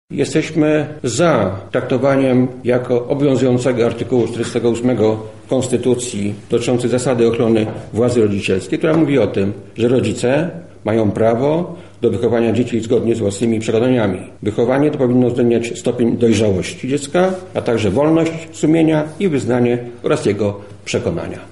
Konferencja Porozumienie 1